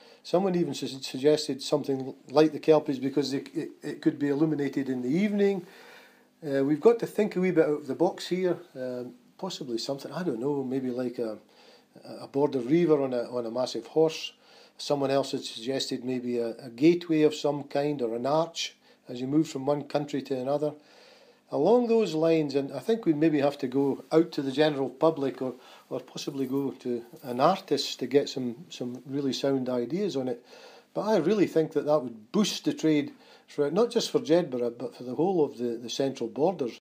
Jedburgh Councillor Jim Brown on building a Kelpie-like statue by the A68